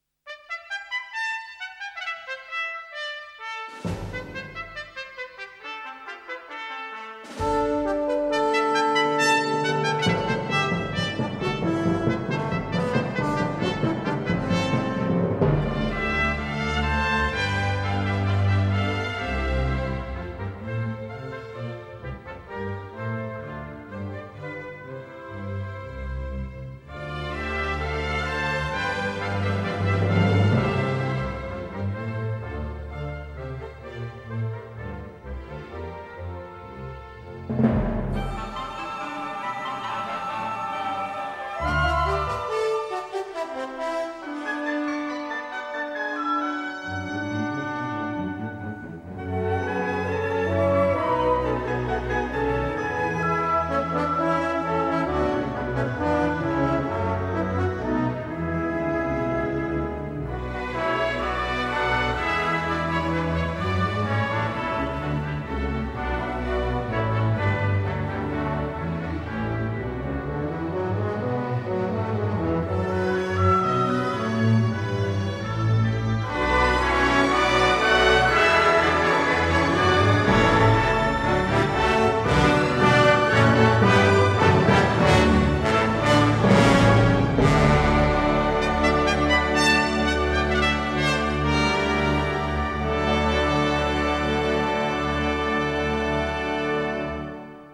Sintonia instrumental llarga d'entrada.